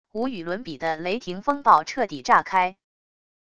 无与伦比的雷霆风暴彻底炸开wav音频